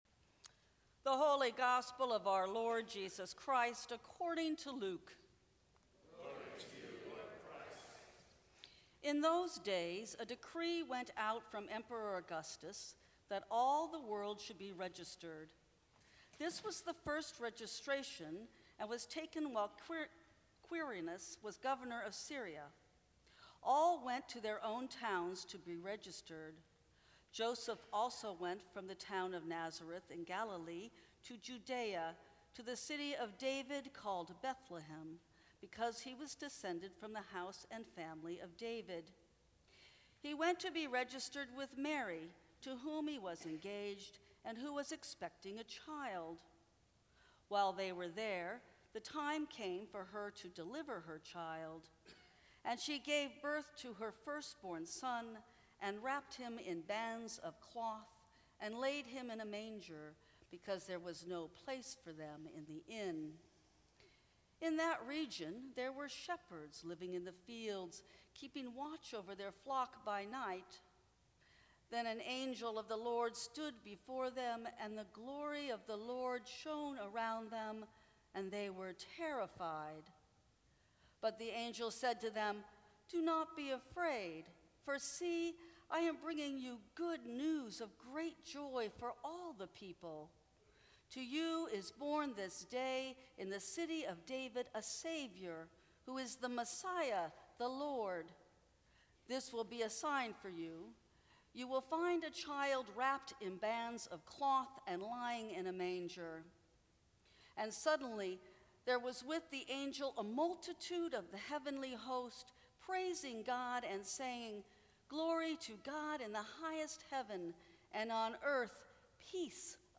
Christmas Eve
Sermons from St. Cross Episcopal Church How Close is God to Us?